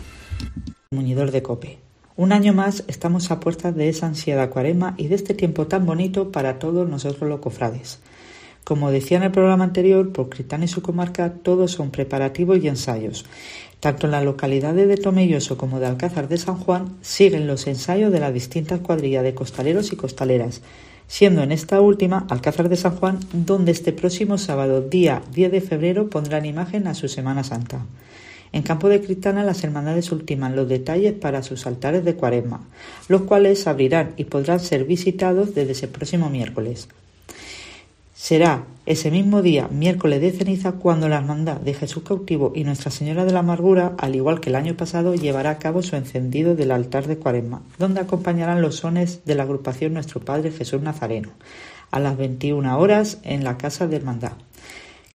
Empezamos esta Cuaresma realizando el programa desde la Casa Hermandade de la Flagelación.